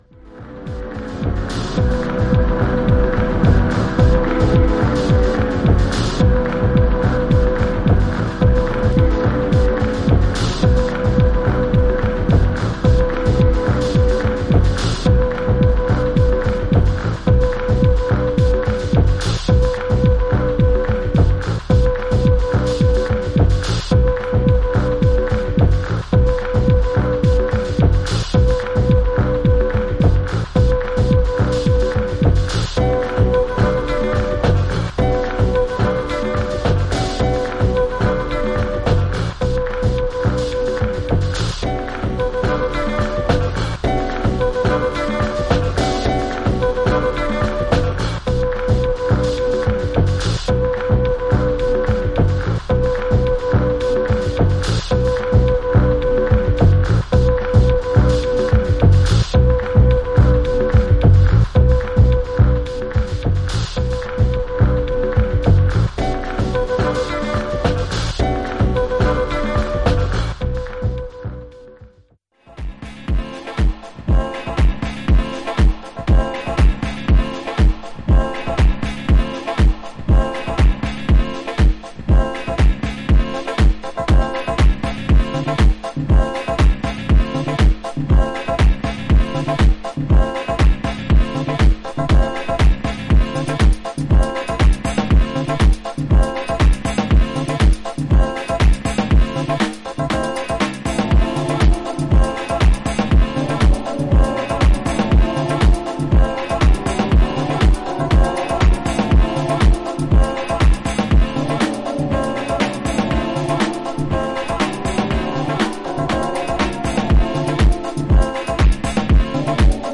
ローファイでスローモーなトラックに、フルートやギターのフレーズが妖術的にリフレインする